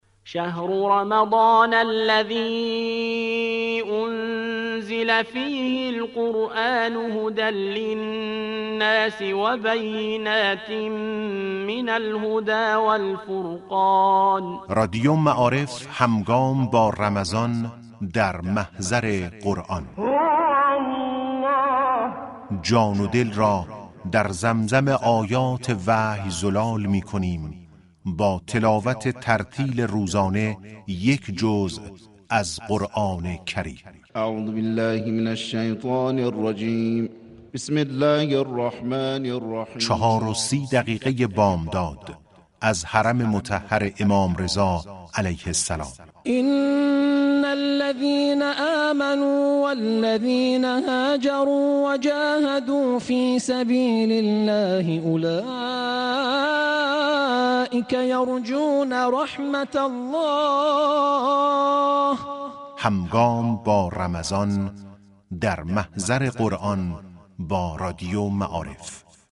رادیو معارف در ماه مبارك رمضان اقدام به پخش زنده تلاوت قرائت قرآن از حرم مطهر رضوی می كند